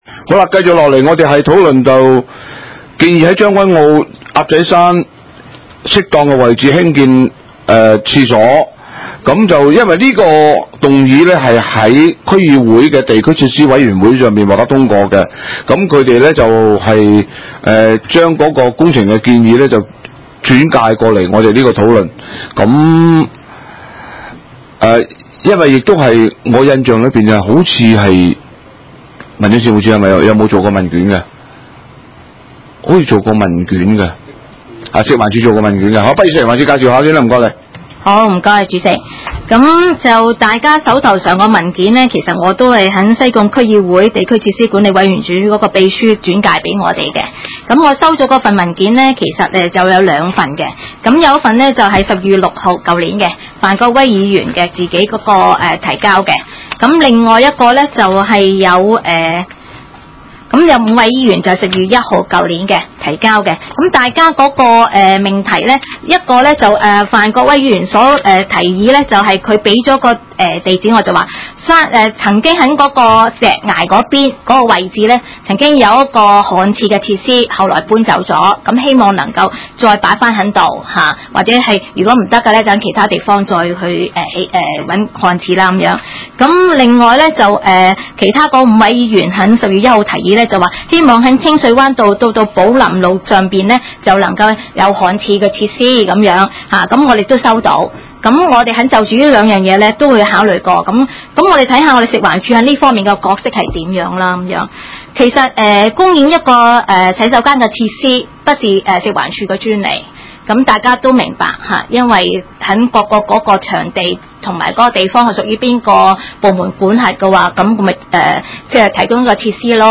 房屋及環境衞生委員會會議